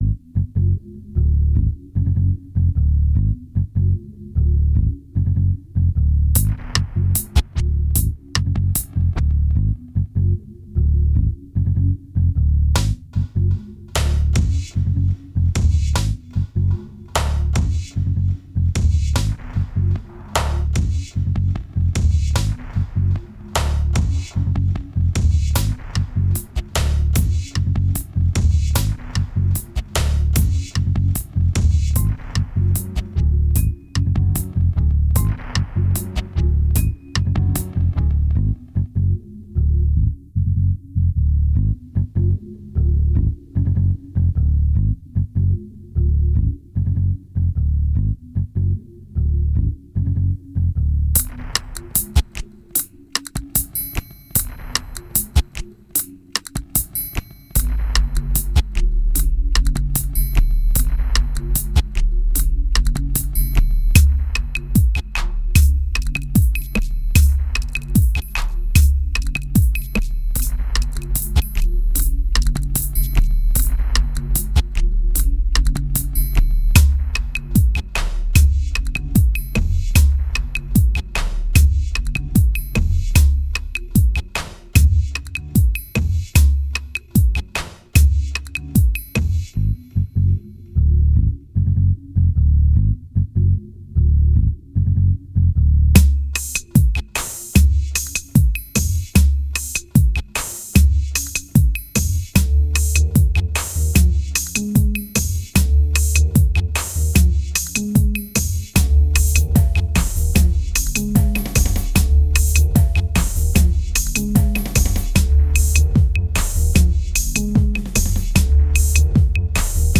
2065📈 - -31%🤔 - 150BPM🔊 - 2009-05-29📅 - -432🌟
Dubstep Prototype Raw Uncut Tape Midi Audition Bass Mass